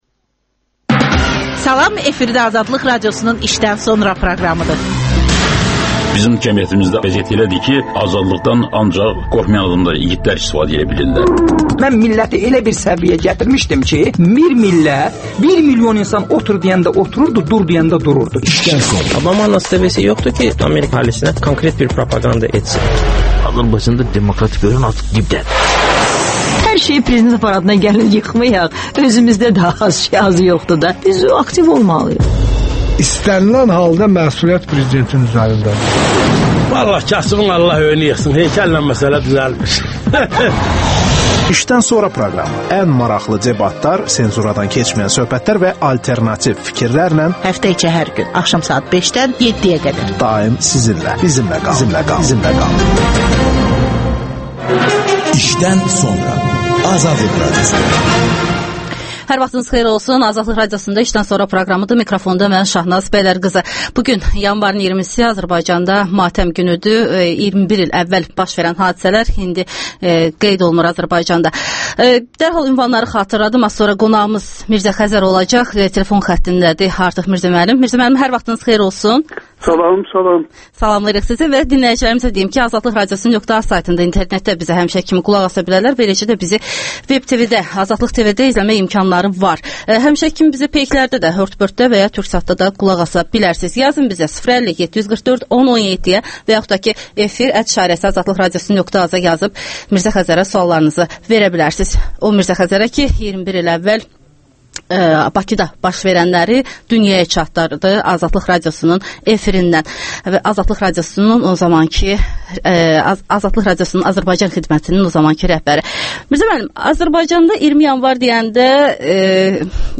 İşdən sonra - Mirzə Xəzərlə söhbət
AzadlıqRadiosunun rəhbəri olmuş və 1990-cı ilin 20 Yanvar faciəsi ilə bağlı məlumatları isti-isti dünyaya çatdırmış Mirzə Xəzər canlı efirdə